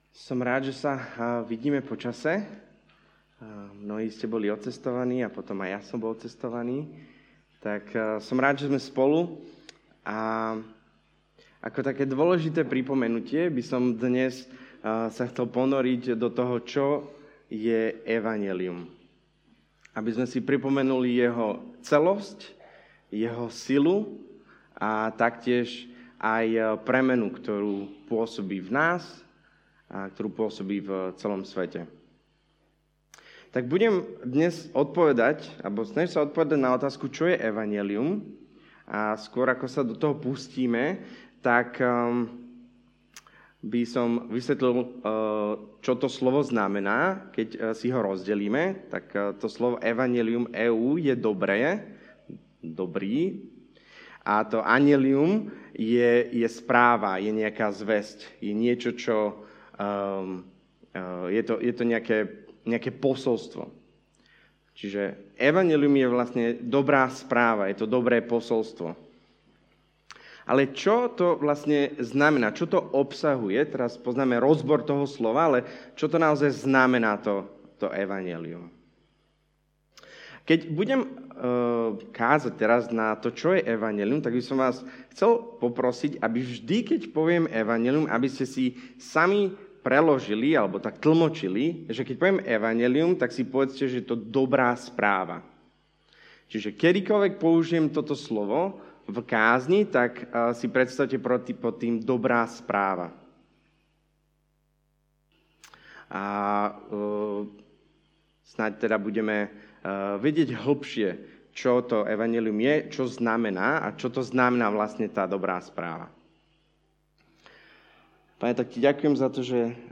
() - Podcast Kázne zboru CB Trnava - Slovenské podcasty